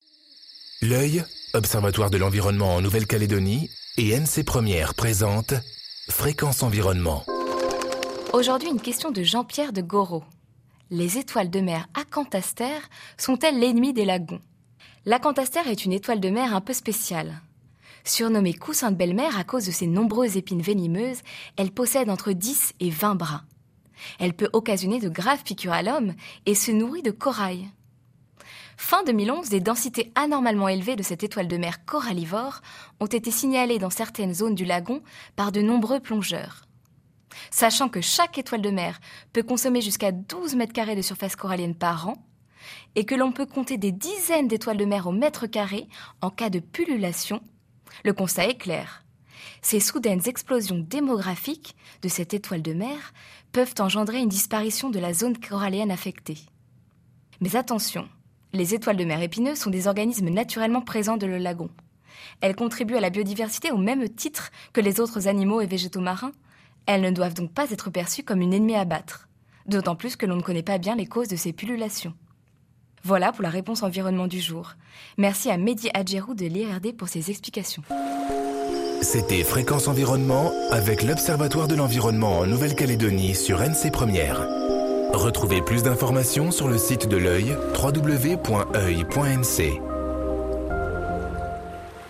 diffusée en décembre 2013 sur NC 1ère